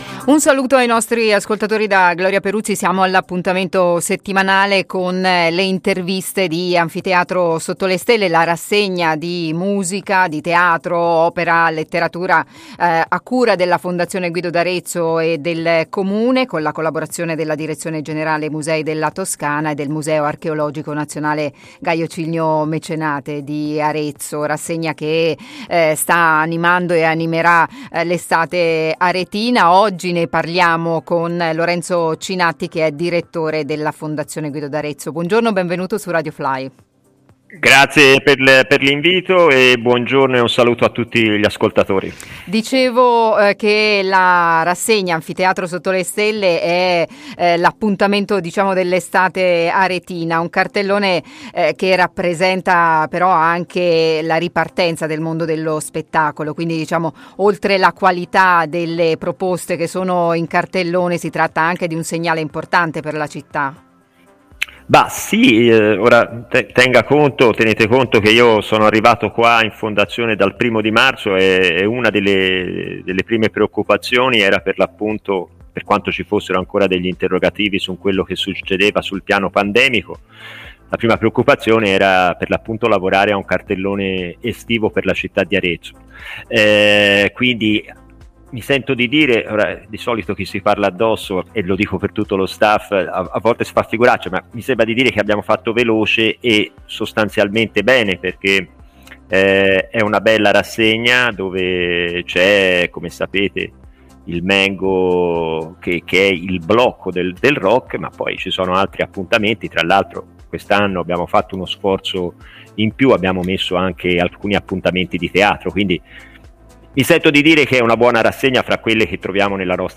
Anfiteatro sotto le Stelle 2021. Intervista